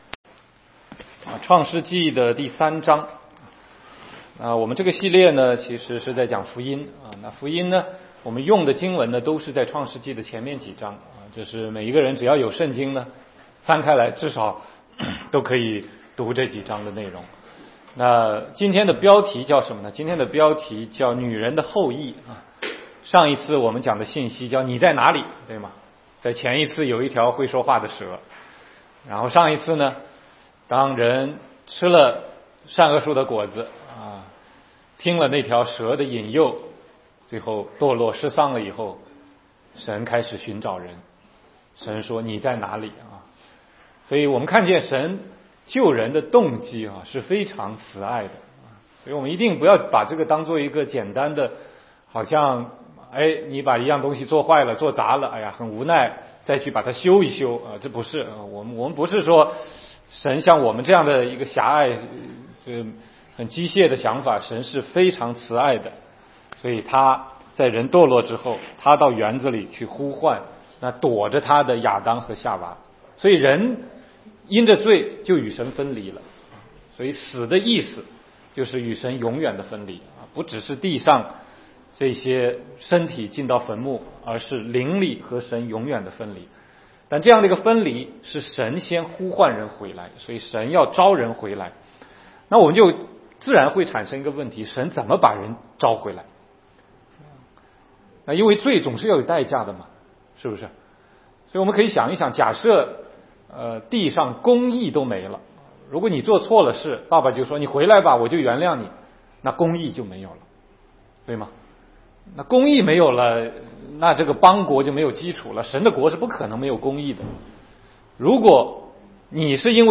16街讲道录音 - 女人的后裔